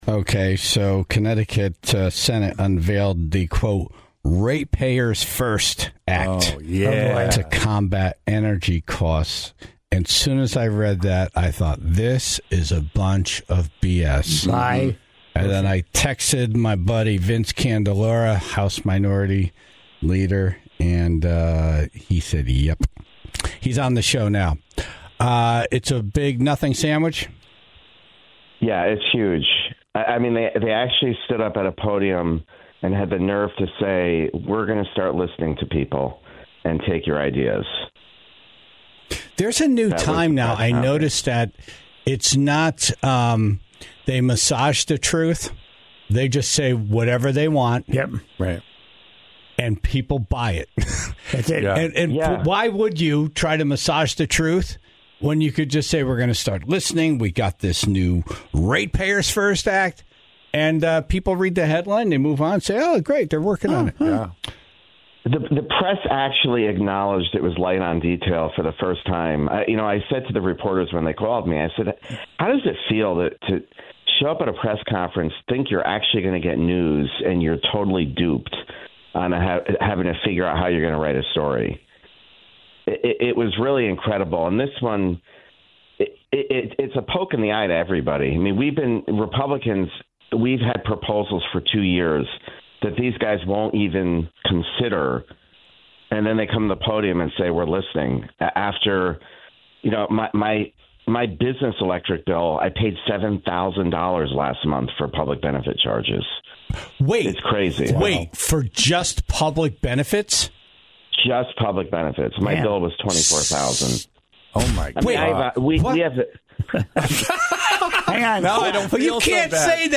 was on the phone